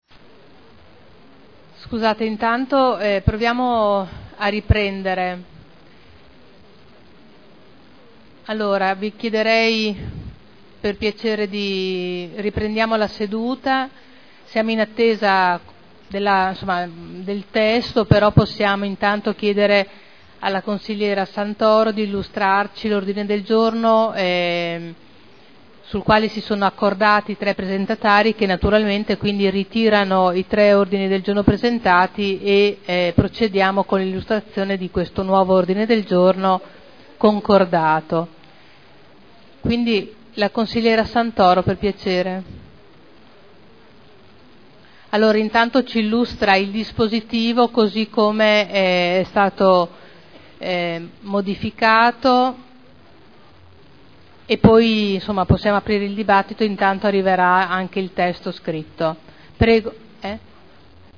Seduta del 3/02/2011. presentazione di un unico ordine del giorno sulla difesa della libertà religiosa nella vicenda Asia Bibi